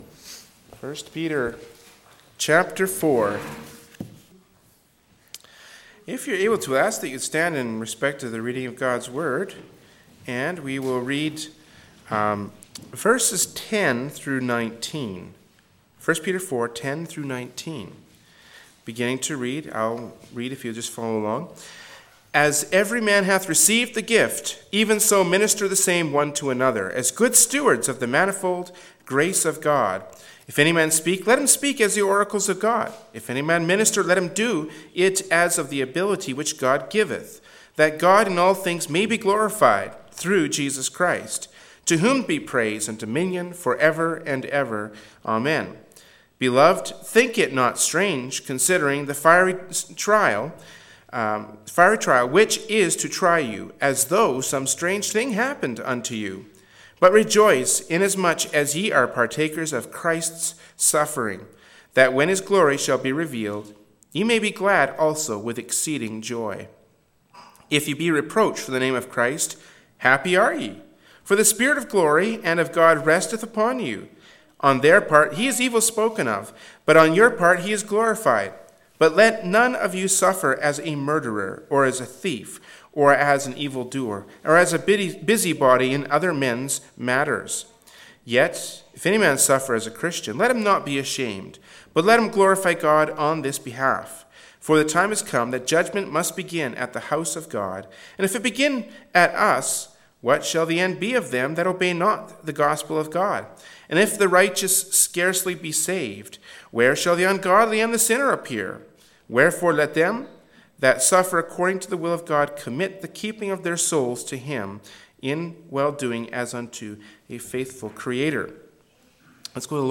Service Type: Wednesday Evening Service